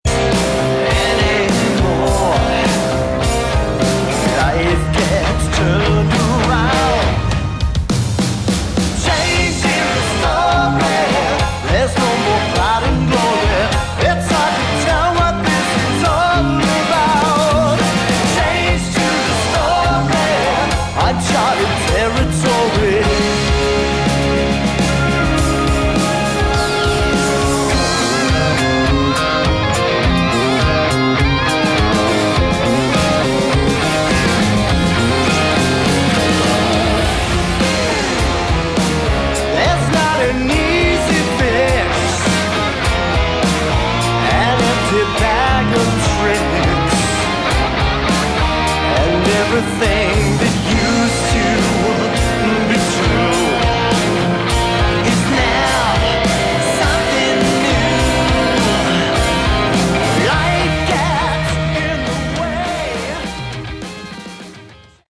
Category: Melodic Rock
vocals
guitar, bass, keyboards
drums